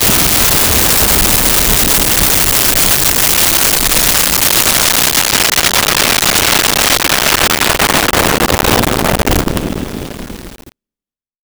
Cannon 2
Cannon_2.wav